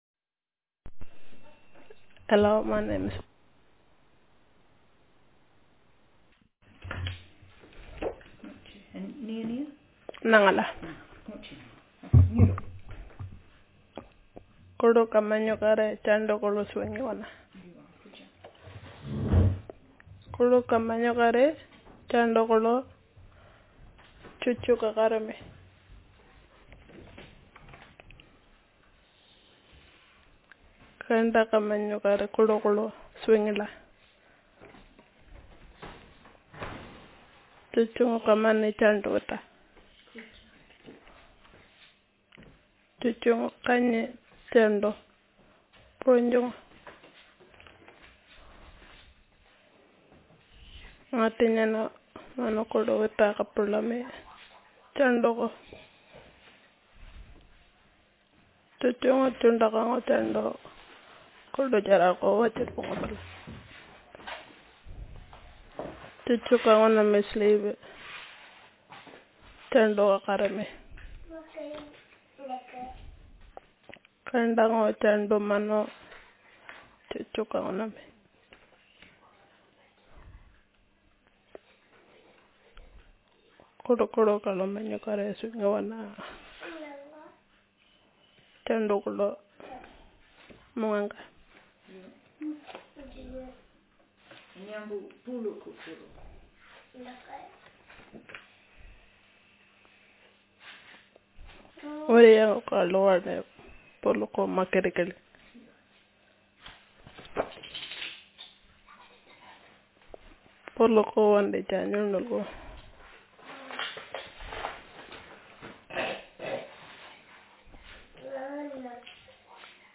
Speaker sexf
Text genrestimulus retelling